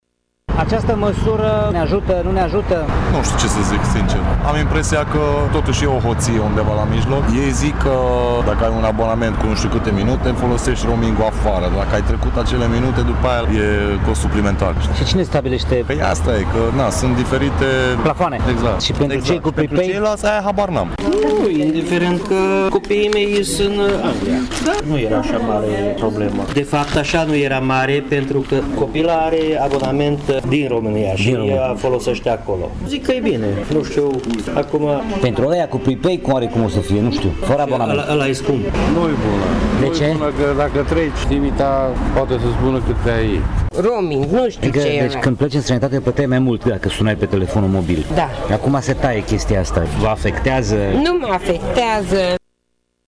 Alții, nu puțini, nici nu știu ce înseamnă roaming, nu părăsesc țara și folosesc telefoane simple, strict pentru convorbiri: